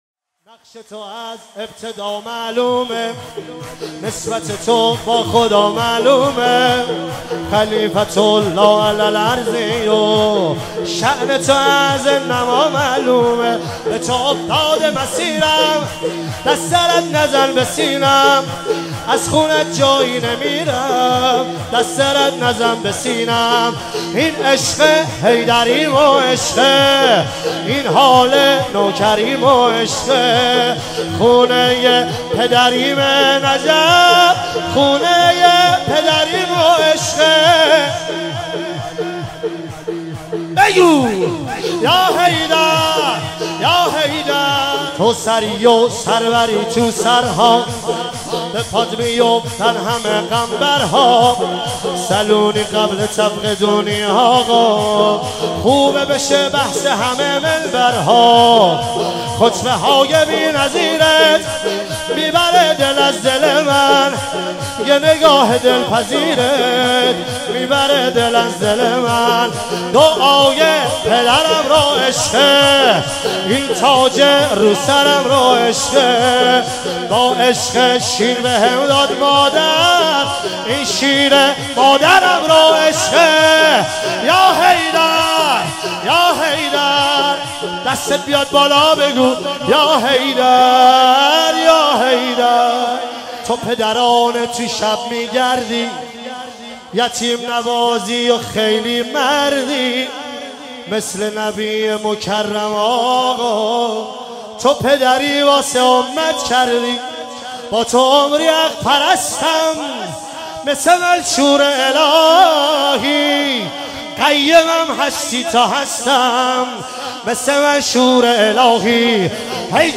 ولادت حضرت معصومه سلام الله علیها